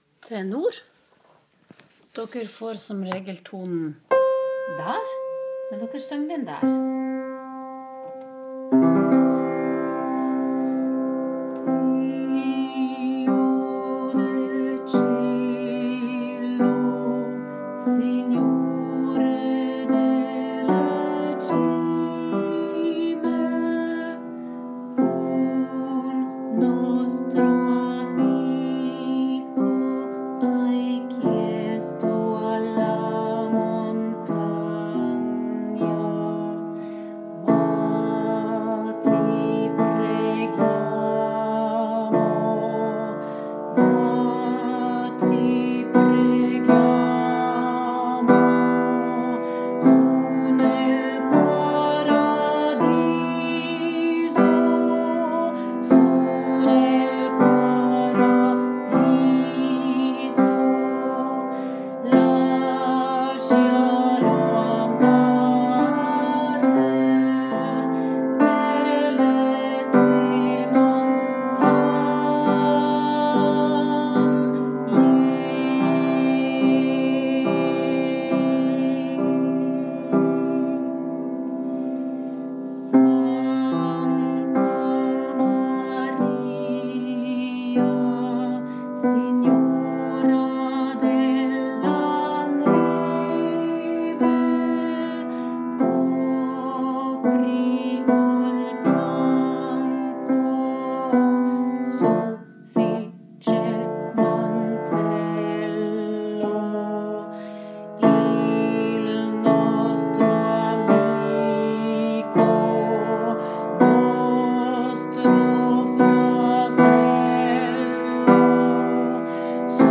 Tenor
SignoreDelleCimeTenor.m4a